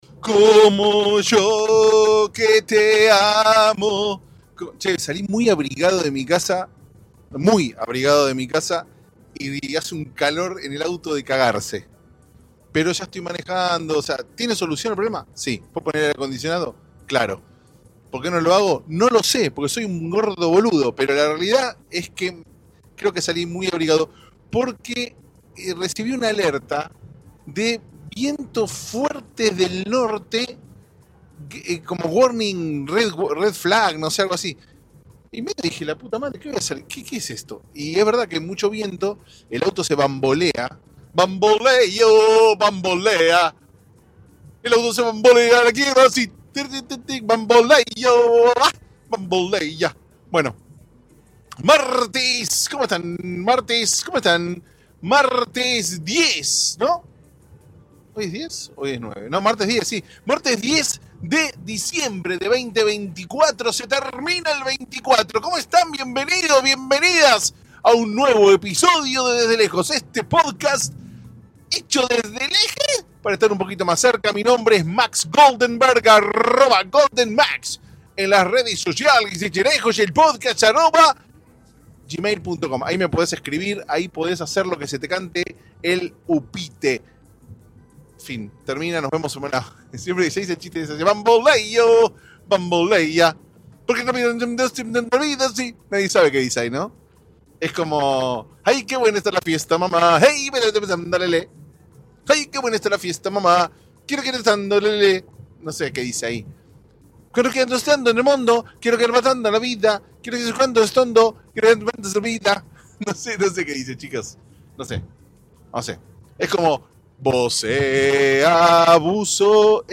Qué lindo que es cantar a los gritos, o no?